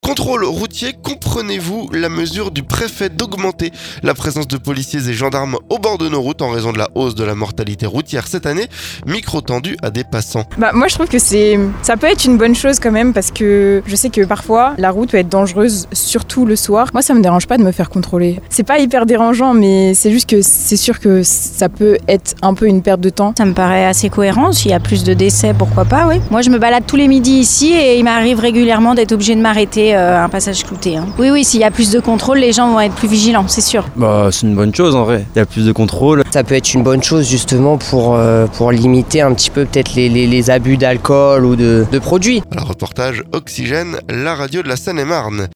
Micro tendu à des passants.